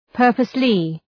Προφορά
{‘pɜ:rpəslı}
purposely.mp3